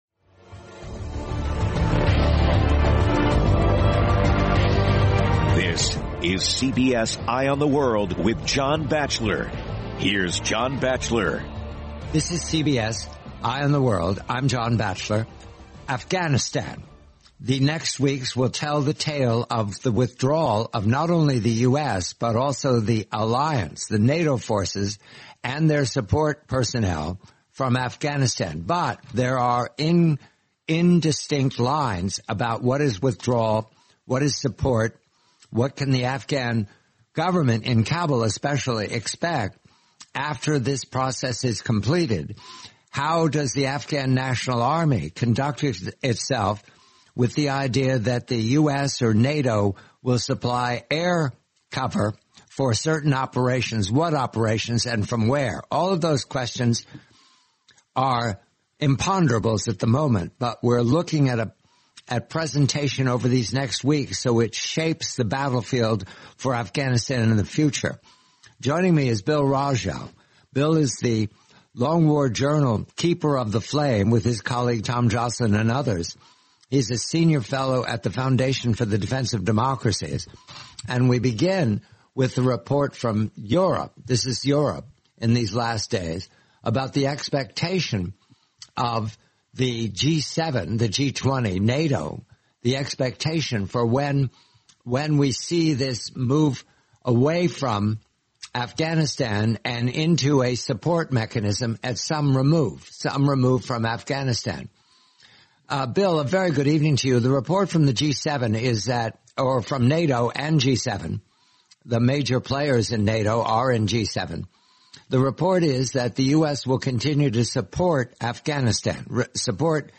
the complete nineteen minute interview